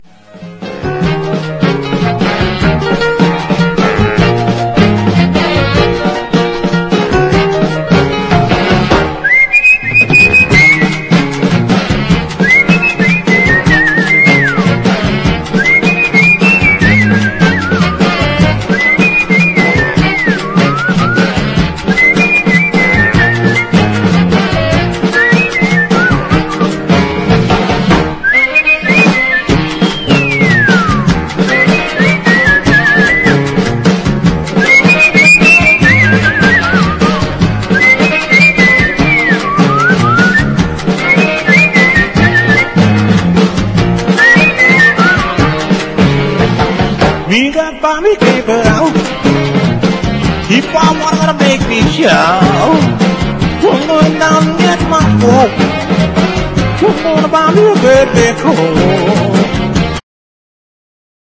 JAZZ / DANCEFLOOR / BIG BAND / EASY LISTENING / JAZZ FUNK
ファンキーなアメリカ空軍ビッグバンド自主盤！